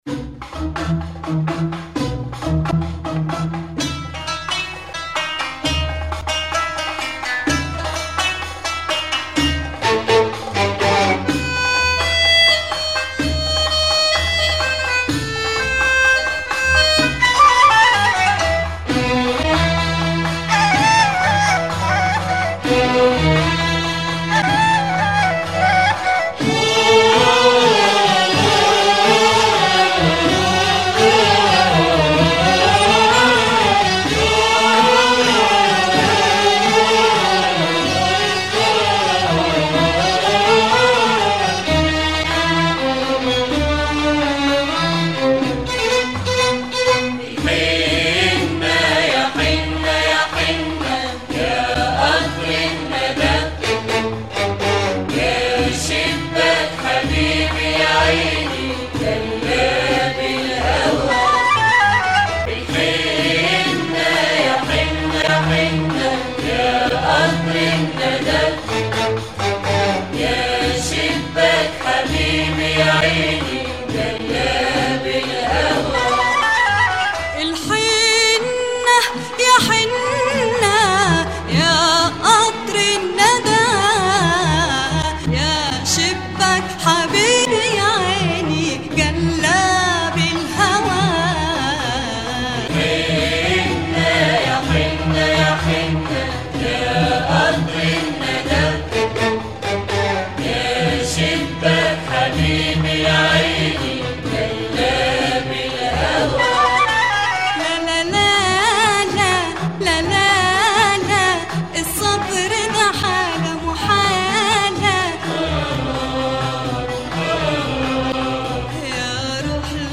Super rare late sixties Egyptian songs